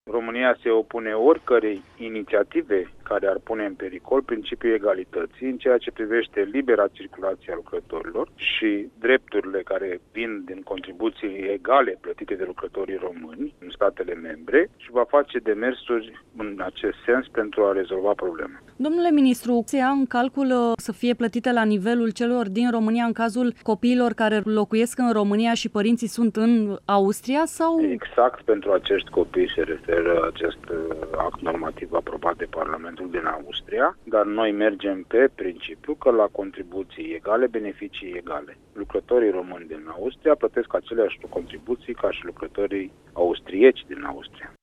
Şi ministrul Muncii Marius Budăi a subliniat, într-o declaraţie pentru RRA, că lucrătorii europeni trebuie trataţi egal în statele membre în care îşi desfăşoară activitatea, dreptul acestora la liberă circulaţie fiind unul dintre fundamentele Uniunii Europene şi ale Pieţei Unice: